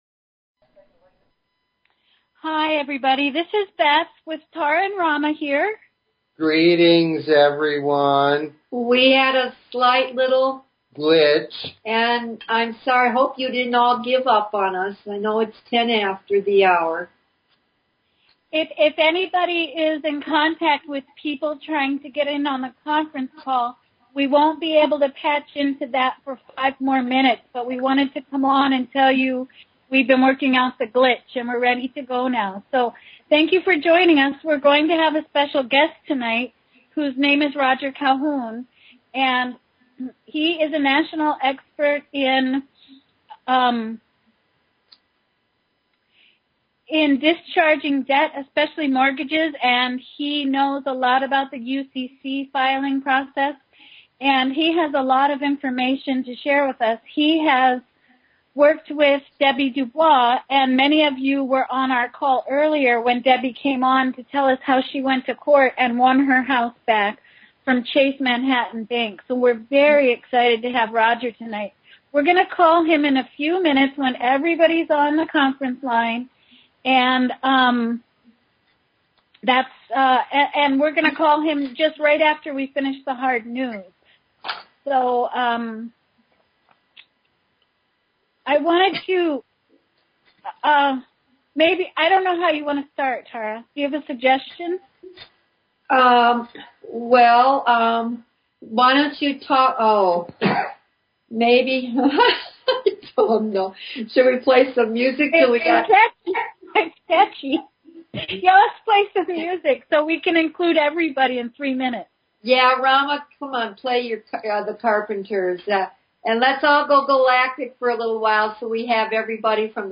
Talk Show Episode, Audio Podcast, Galactic_Healing and Courtesy of BBS Radio on , show guests , about , categorized as